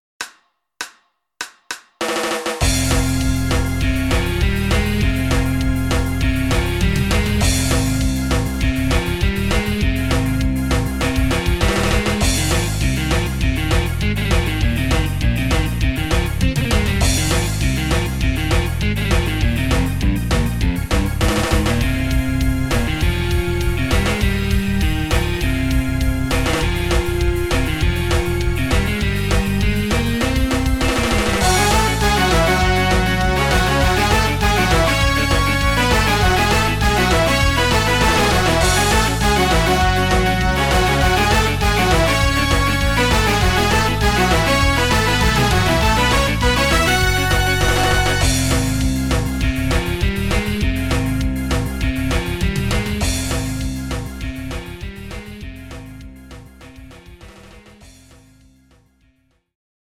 「和」「雅」といった叙情的なものとElectricGuitarサウンドの調和・融合を目指した
Play All Instruments